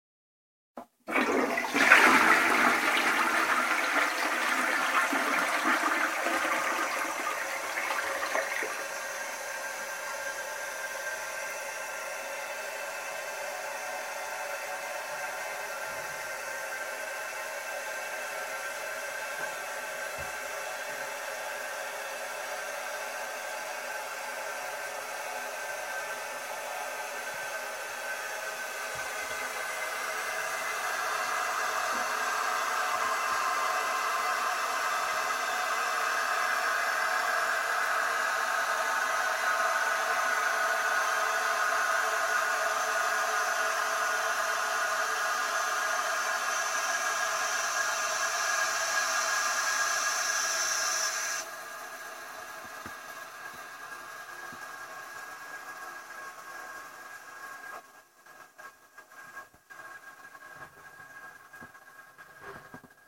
冲厕
描述：挪威人在小浴室里冲马桶的声音被间谍麦克风捕捉到。
Tag: 环境 - 声音的研究 冲水 马桶